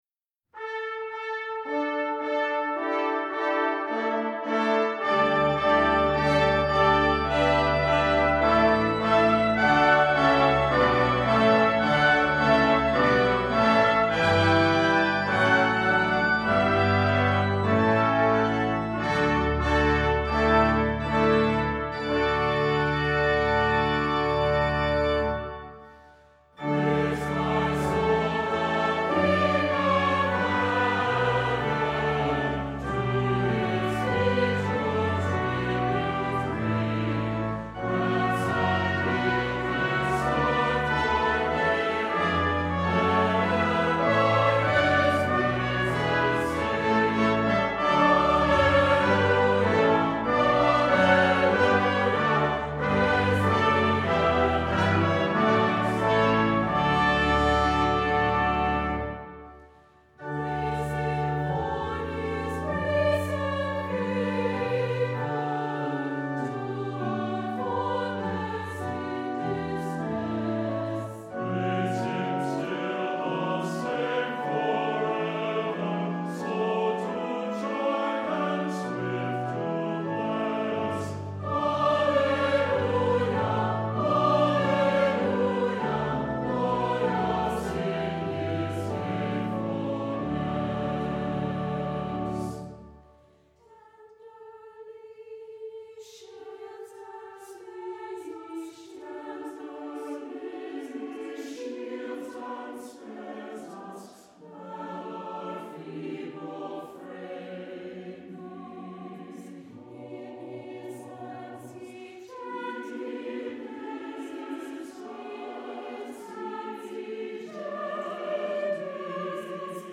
Voicing: SATB, Optional Congregation